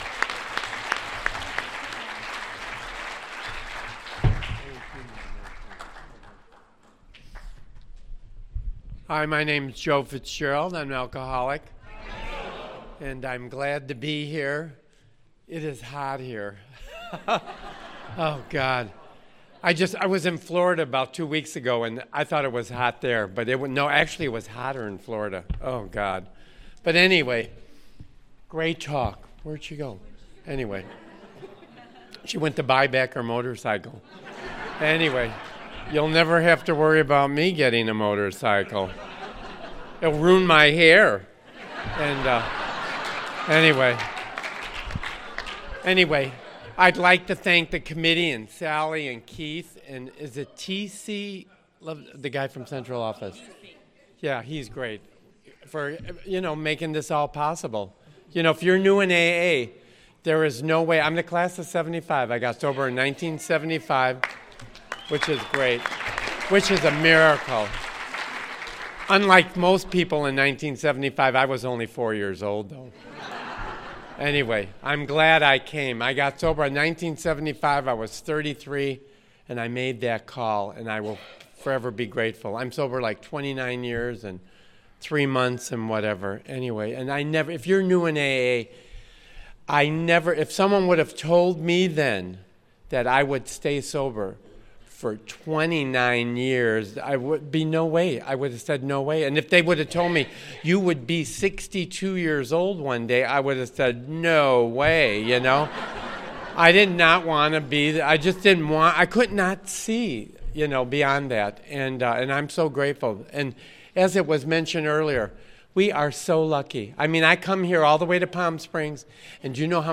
Friday Opening AA Speaker